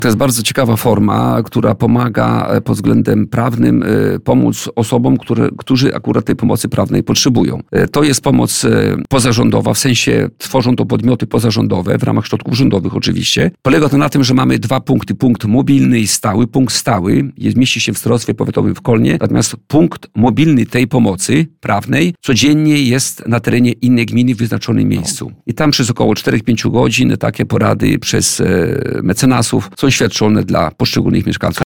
Starosta kolneński Tadeusz Klama na antenie Radia Nadzieja wyjaśniał, jak w praktyce działa udzielanie bezpłatnych porad.